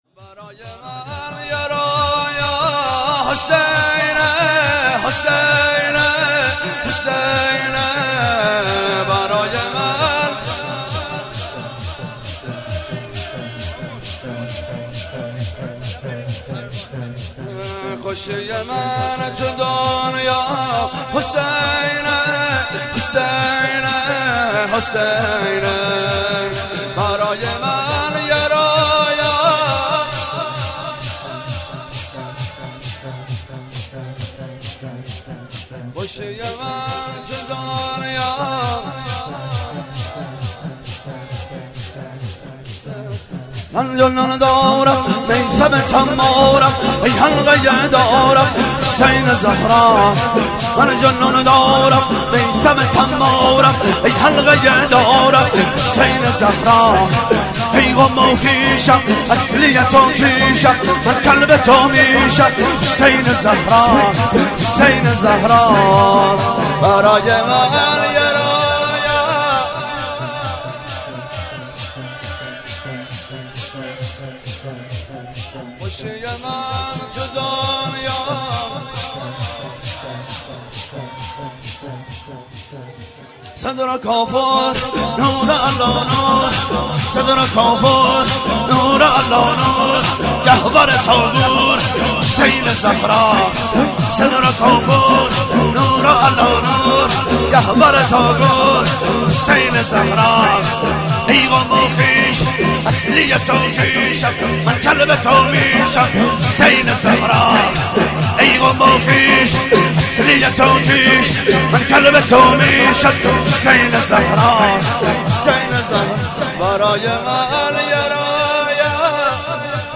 شور - برای من یه رویا حسینه
شب دوم ویژه برنامه فاطمیه دوم ۱۴۳۹